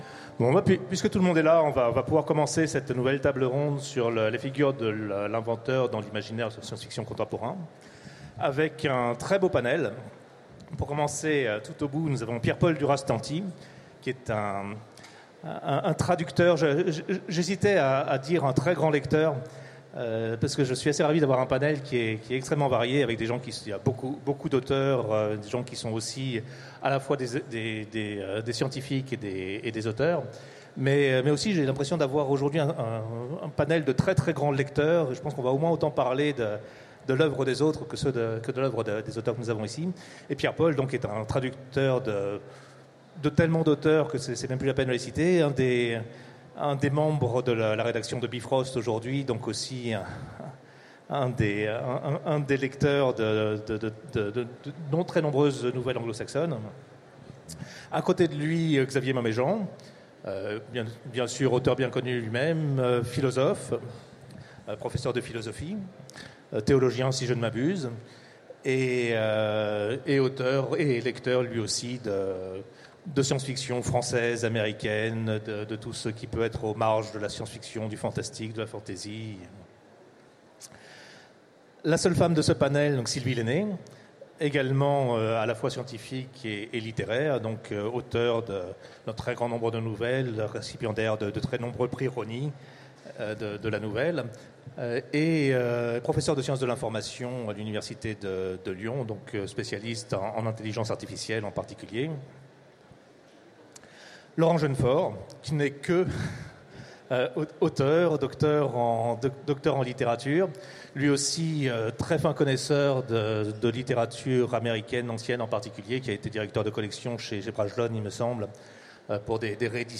Utopiales 2016 : Conférence Figures de l’inventeur dans l’imaginaire contemporain